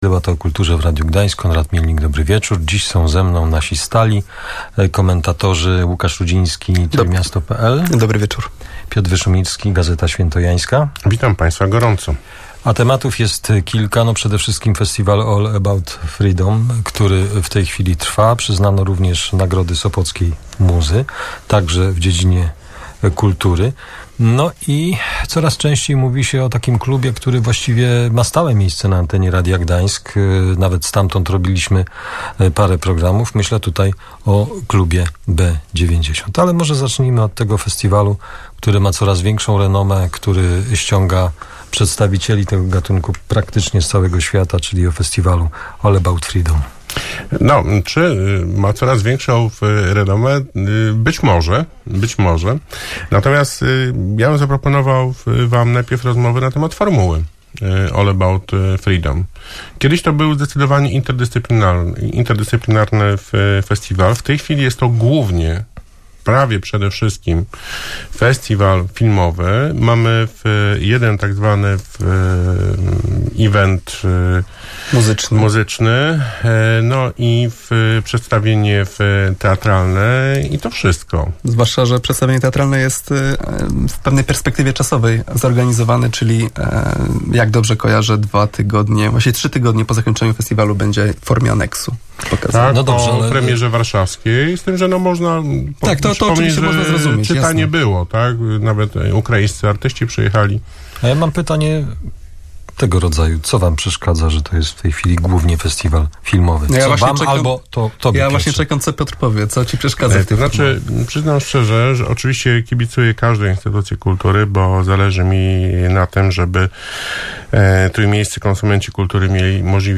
Festiwal All About Freedom, przyznanie nagród Sopocka Muza i działalność klubu B90 w Gdańsku - o tym rozmawiali uczestniczy Debaty